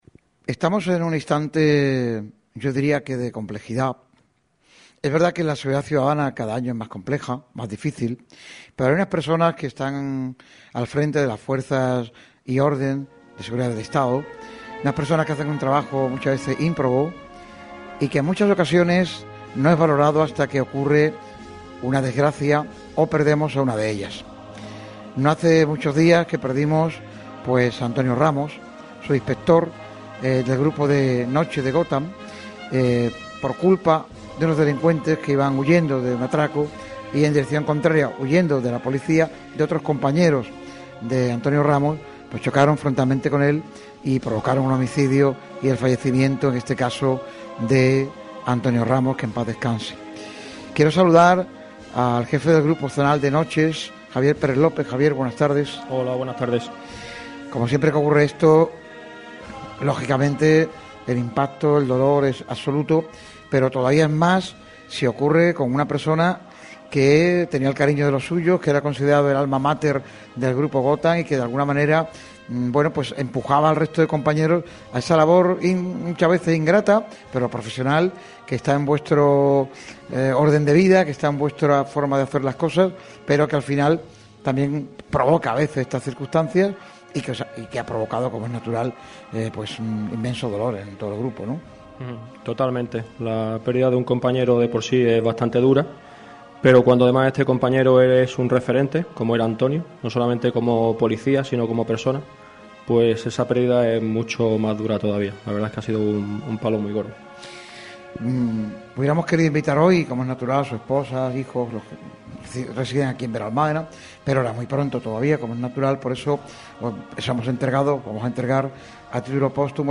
Lo ha hecho en un programa especial celebrado en la Casa de la Cultura de Benalmádena con el patrocinio de ACOSOL y el propio Ayuntamiento de Benalmádena.